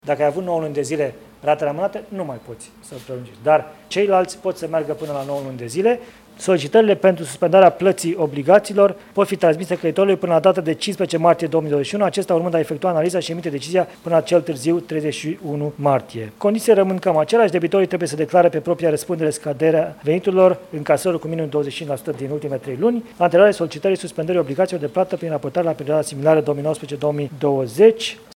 Premierul Florin Cîțu, după ședința de Guvern: